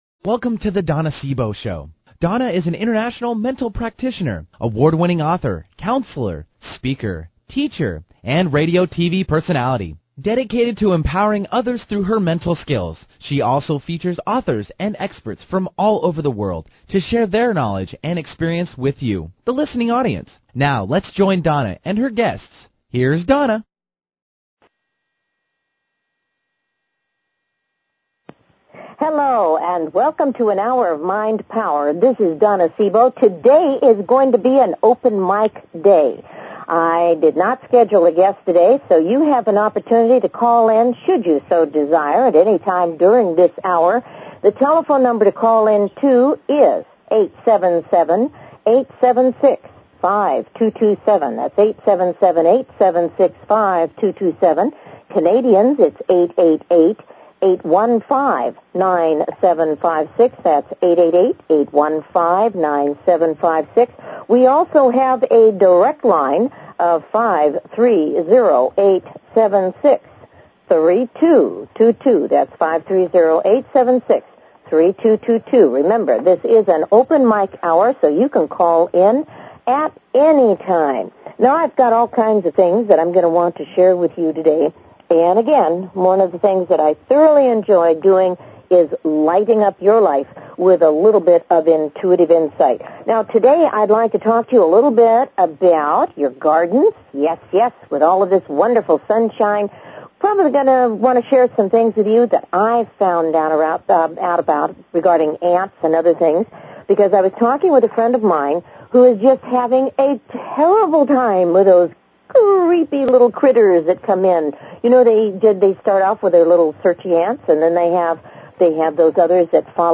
Talk Show Episode, Audio Podcast
This entire hour will be open for phone calls and I will be sharing all kinds of stories, recipes and other information with you.